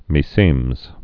(mē-sēmz)